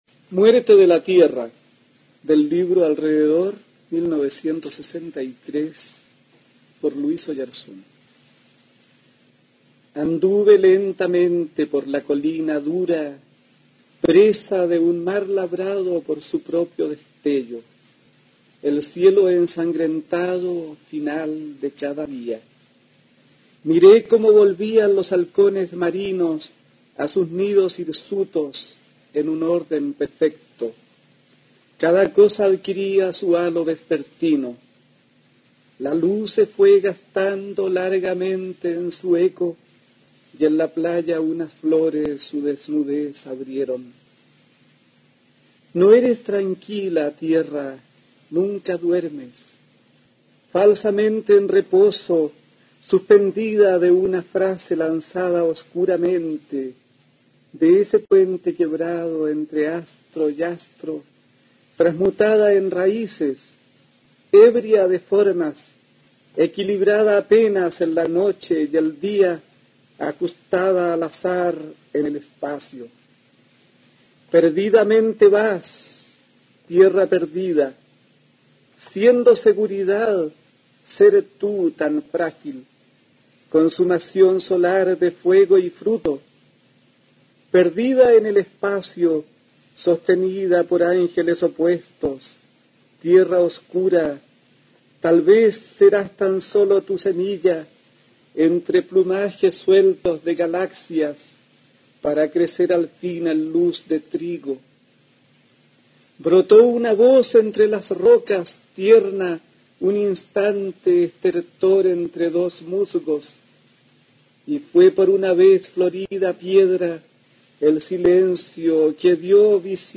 Aquí se puede escuchar al poeta, profesor y ensayista chileno Luis Oyarzún (1920-1972) leyendo su poema Muerte de la tierra, del libro "Atardecer" (1963).